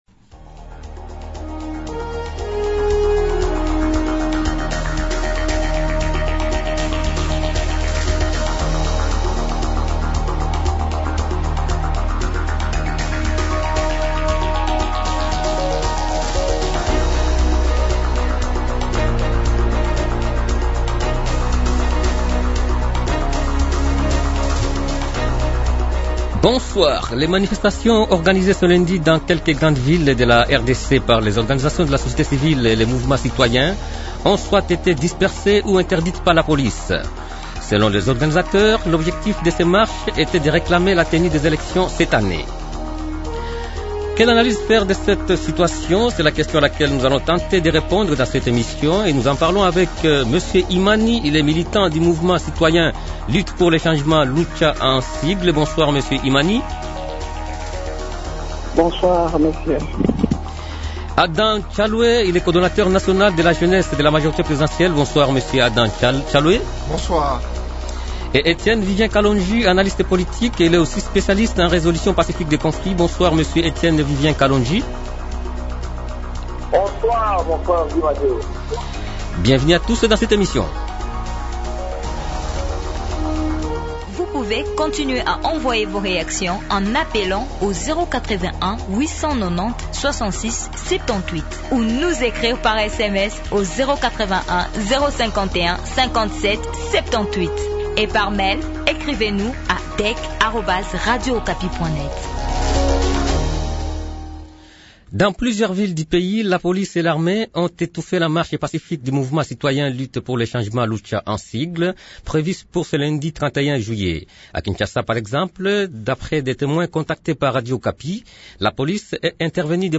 Les débatteurs de ce soir sont :